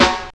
TRASH CAN.wav